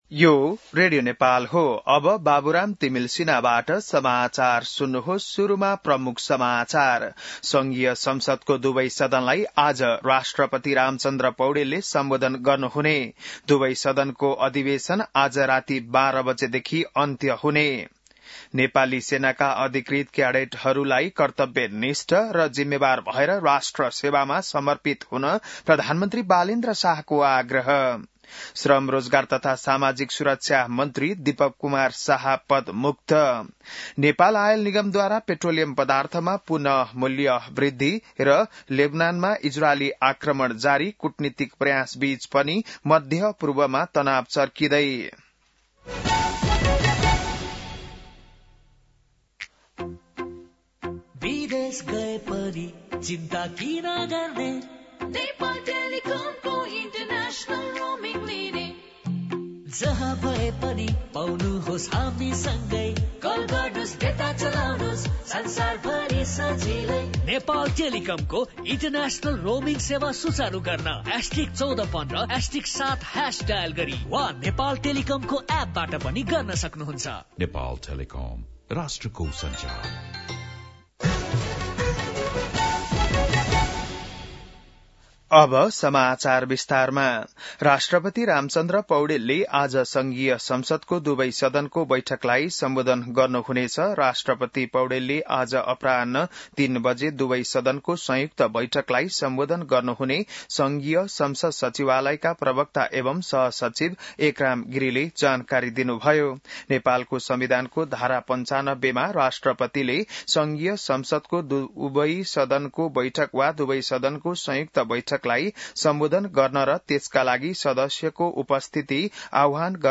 बिहान ७ बजेको नेपाली समाचार : २७ चैत , २०८२